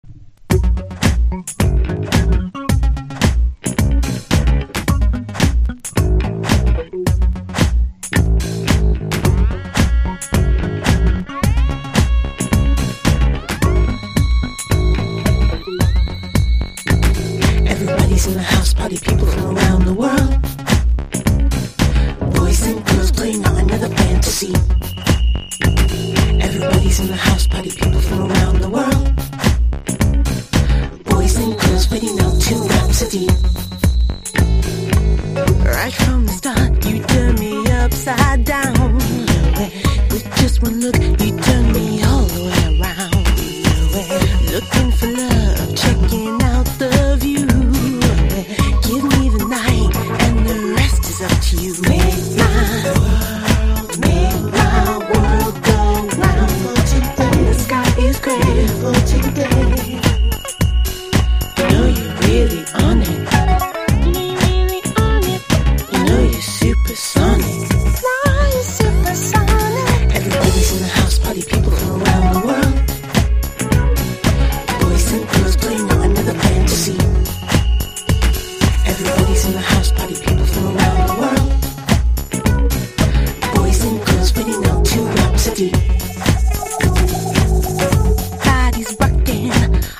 1. 00S ROCK >
INDIE DANCE